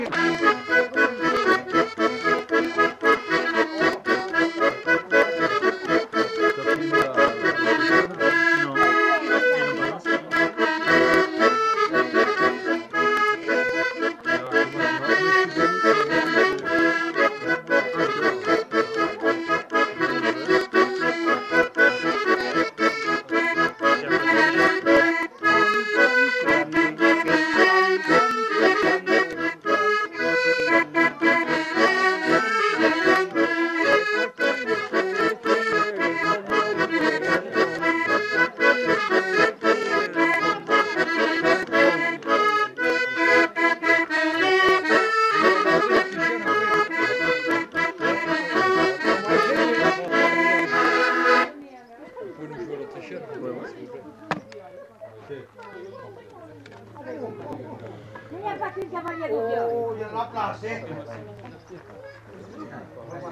Aire culturelle : Grandes-Landes
Lieu : Luxey
Genre : morceau instrumental
Instrument de musique : accordéon diatonique
Danse : polka piquée